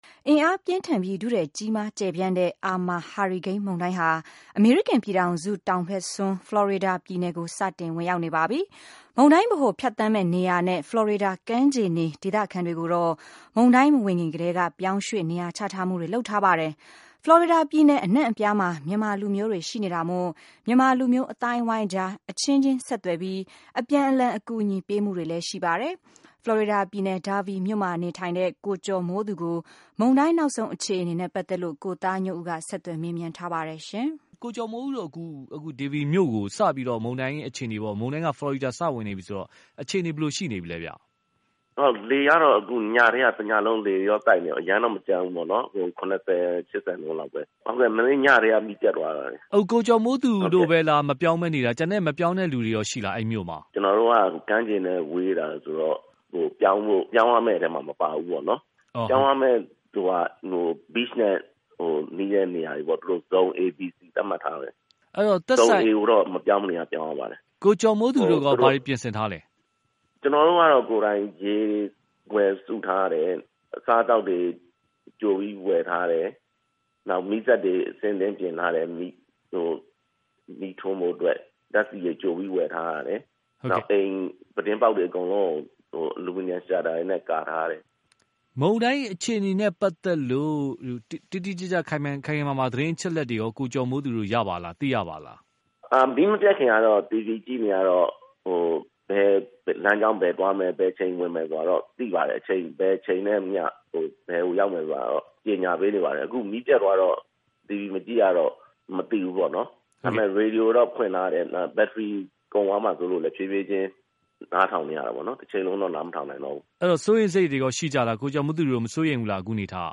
Irma ဟာရီကိန်းအခြေအနေ ဖလော်ရီဒါနယ်ခံ မြန်မာတဦးနဲ့မေးမြန်းချက်